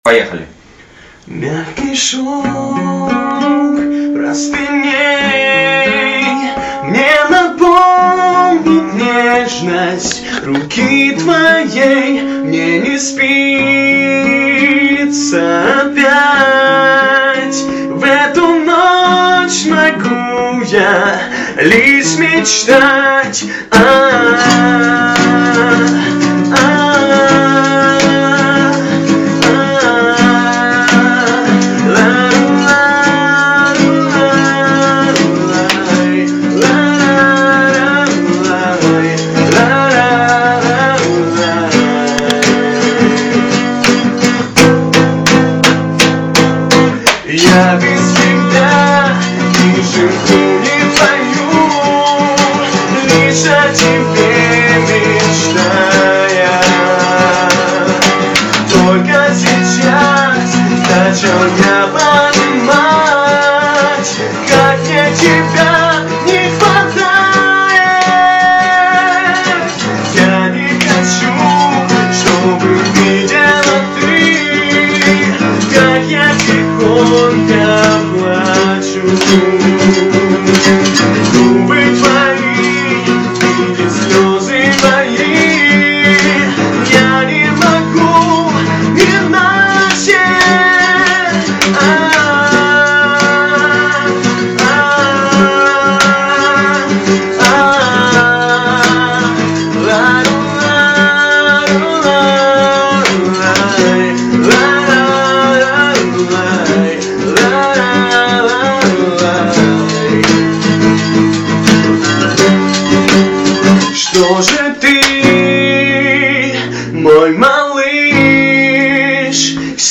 Голос хороший и похож очень